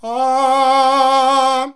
AAAAAH  C.wav